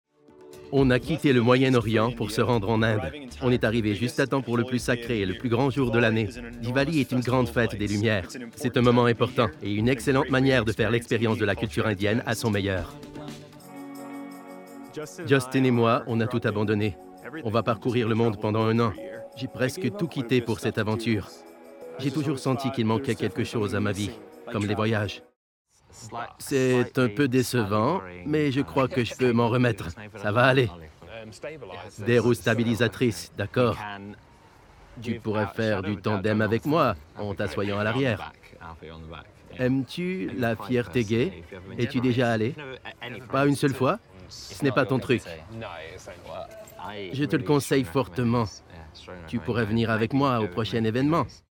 Démo audio